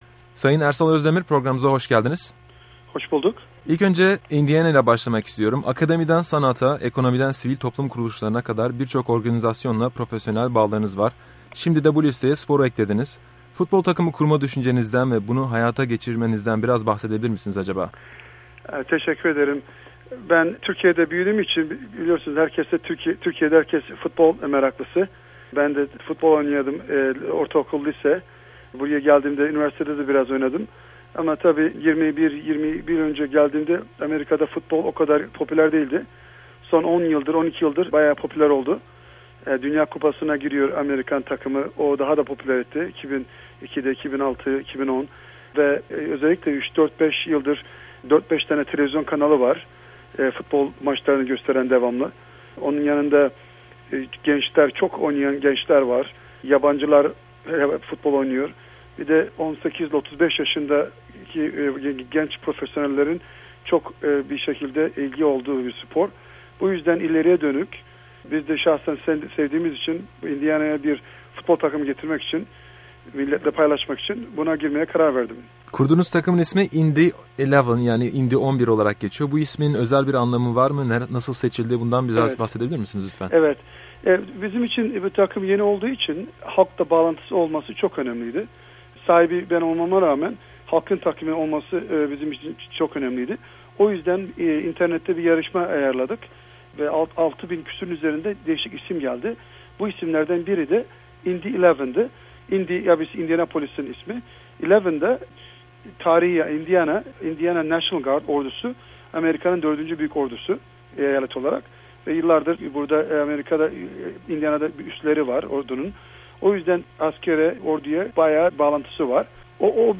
Ersal Özdemir Interview